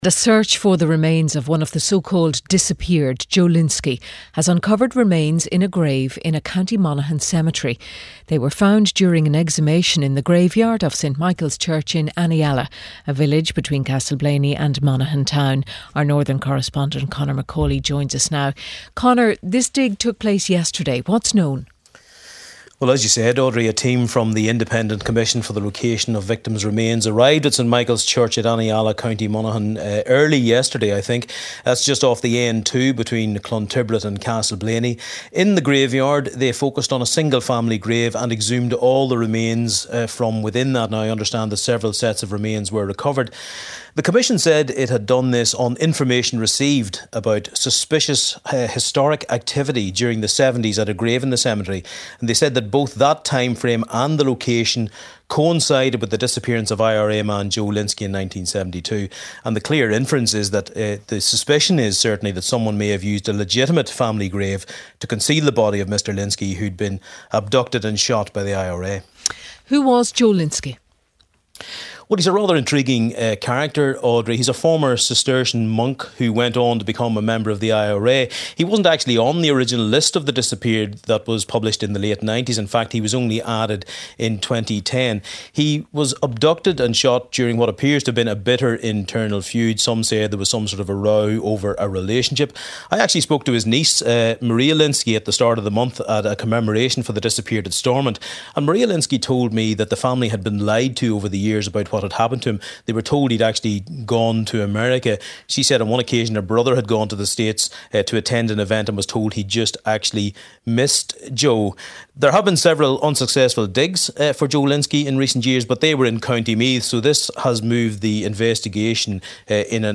Daily News Ireland RTÉ RTÉ Radio 1 Business News International News News